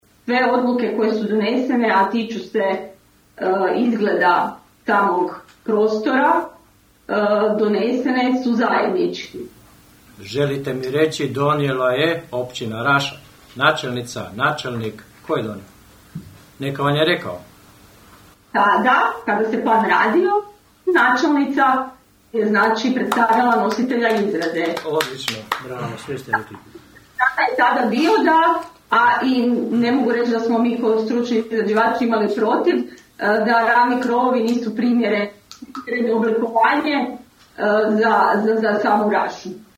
Sa sedam glasova za vijećnika vladajuće većine i šest glasova protiv oporbenih vijećnika na sinoćnjoj su sjednici Općinskog vijeća Raše prihvaćene IV. Izmjene i dopune Prostornog plana uređenja Općine Raša.